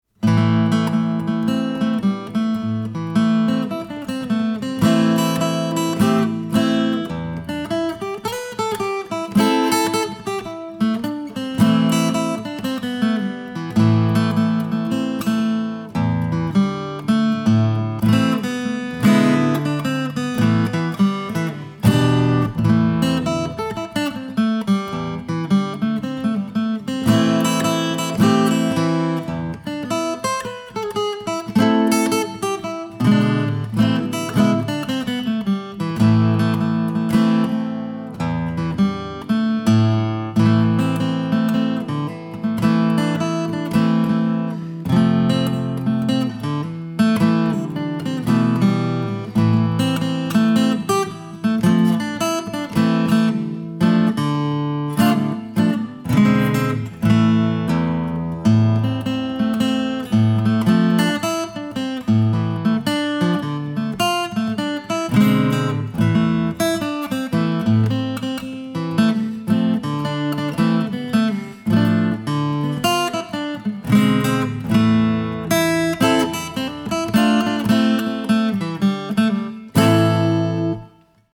scored in A minor